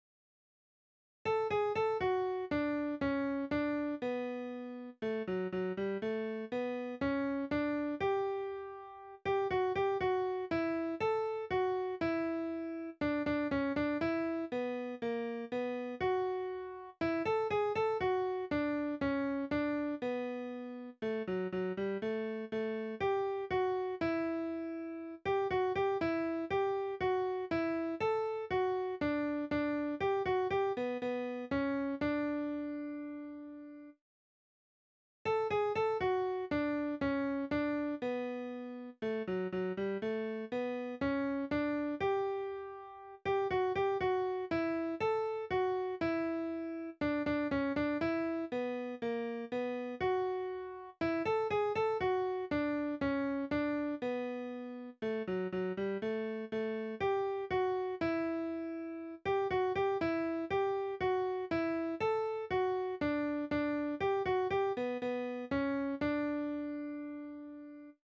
(melodin inknackad “midi-style”).